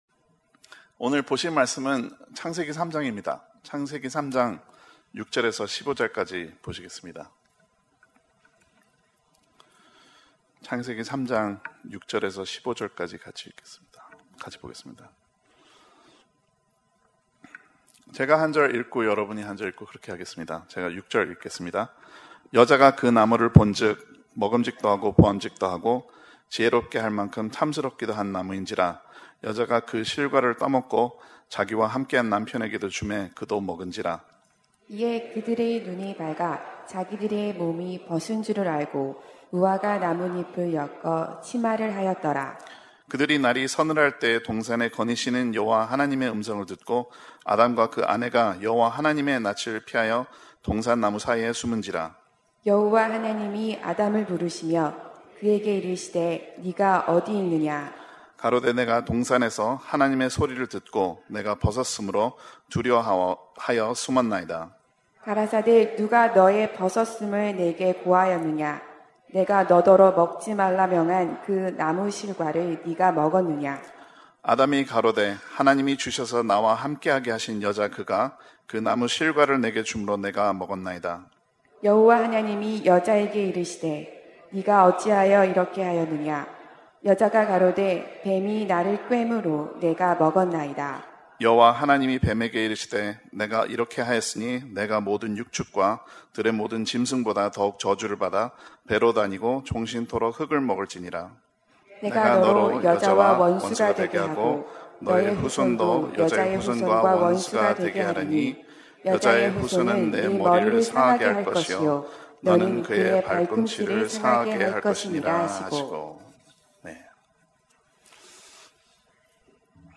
주일예배 - 창세기 3장 6절~15절 주일 1부